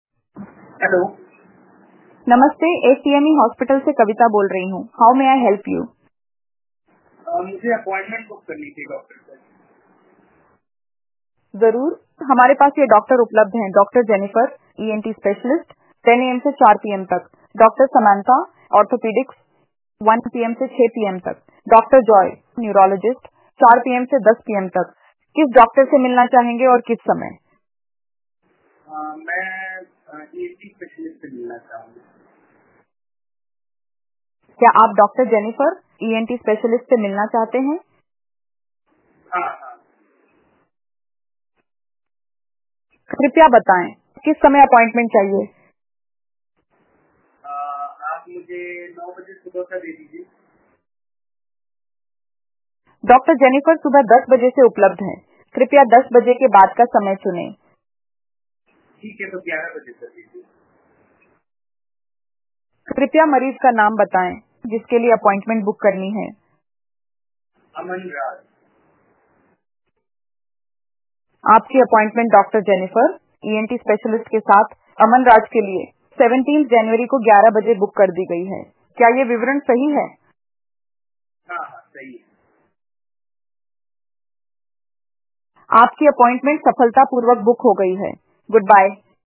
Reimagine patient engagement with Callmatic’s AI Voice Bot.
Hospital-Appointment-booking_website_hindi.wav